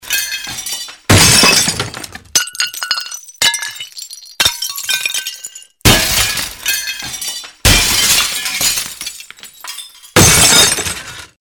Звуки грохота, падения
На этой странице собраны реалистичные звуки грохота и падений: от звонкого разбития посуды до тяжелых обвалов.
грохочущий звук разбитого стекла